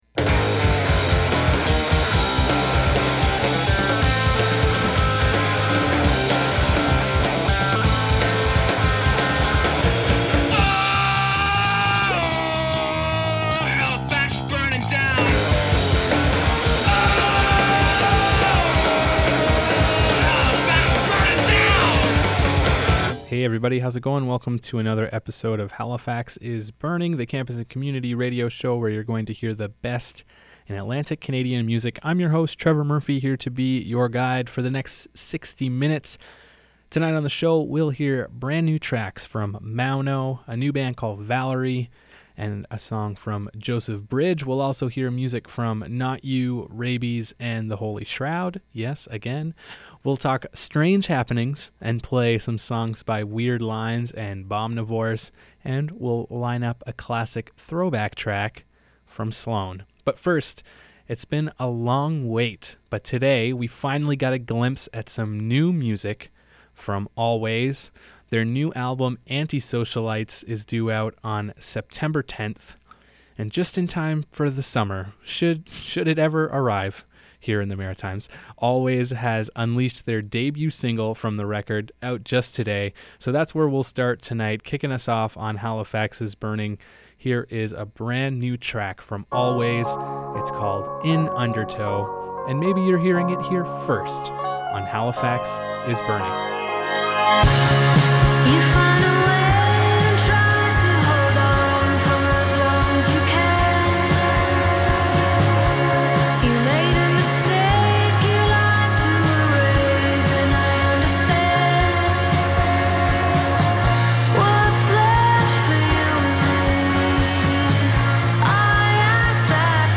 The best independent East Coast music.